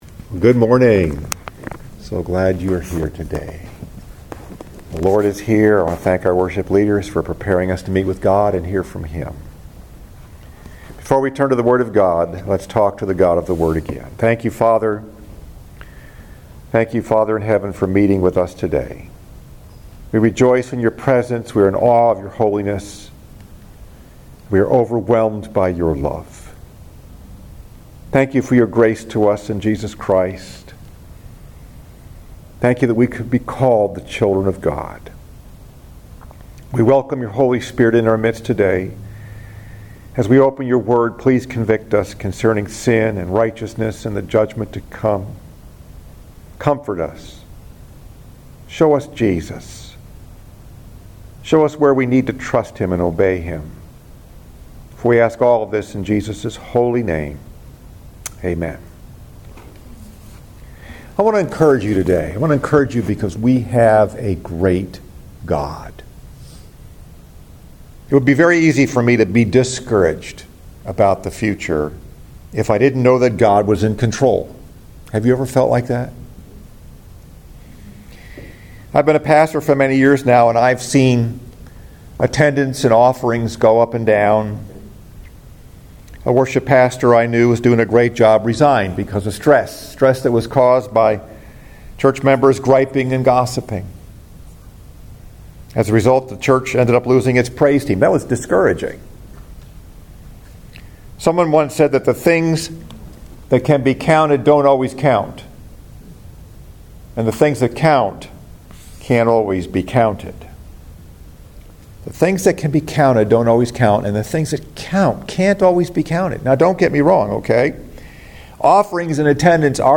Message: “No Excuses” Scripture: Exodus 3 & 4